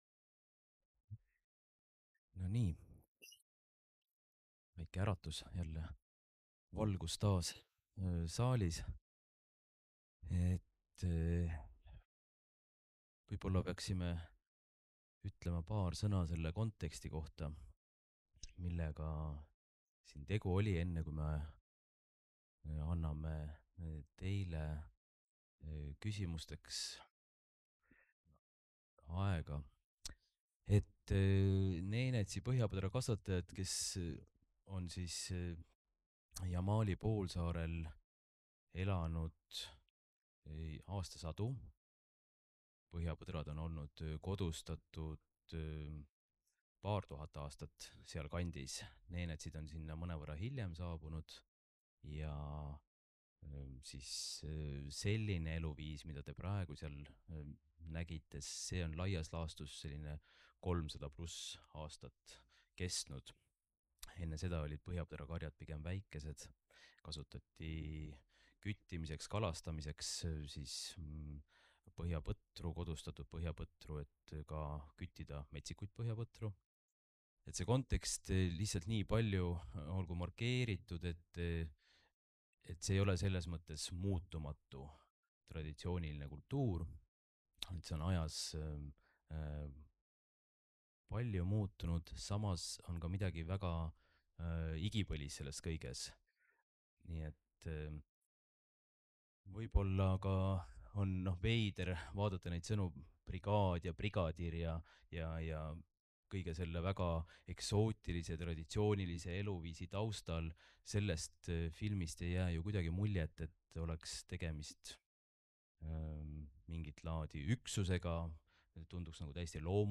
Pärast toimus vestlus